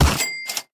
snd_cash.ogg